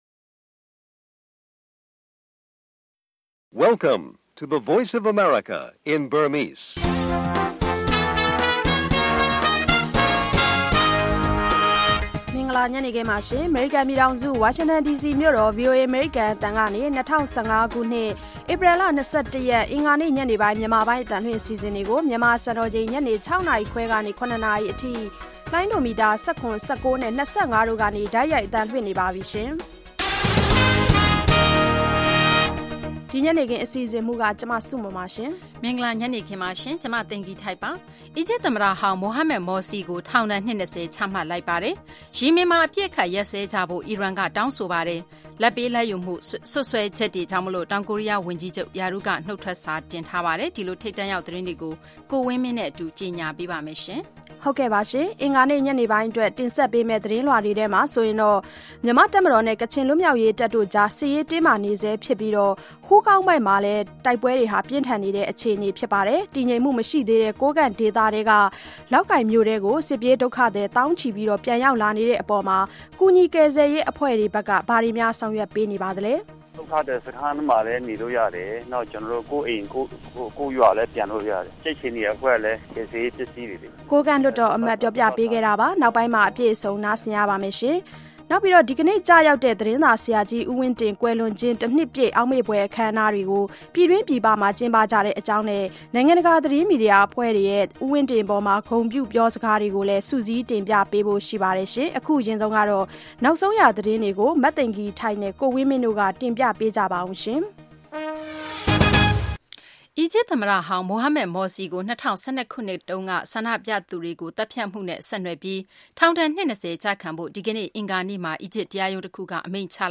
ဗွီအိုအေမြန်မာပိုင်းမှ နောက်ဆုံးထုတ်လွှင့်ခဲ့သည့် ရေဒီယိုအစီအစဉ် တခုလုံးကို ဤနေရာတွင် နားဆင်နိုင်ပါသည်။